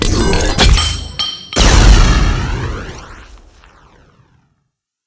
plagrenade.ogg